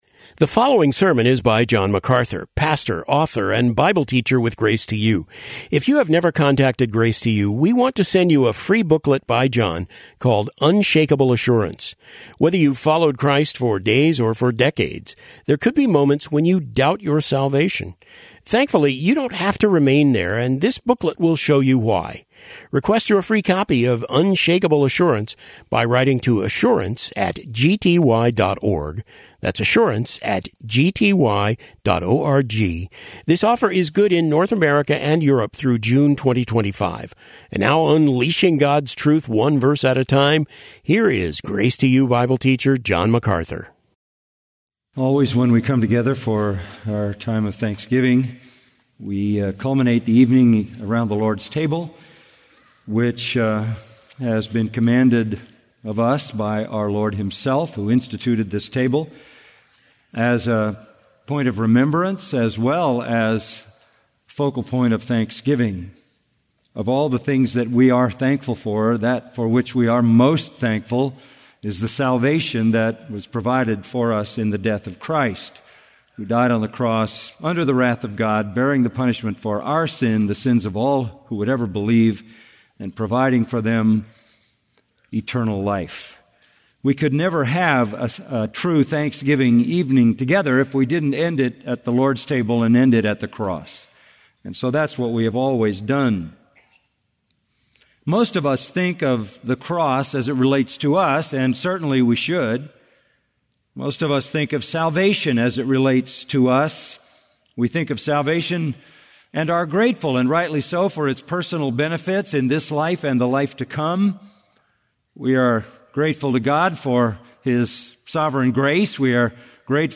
John MacArthur routinely preaches a sermon more than once on the same date, during different worship services at Grace Community Church. Normally, for a given sermon title, our website features the audio and video that were recorded during the same worship service.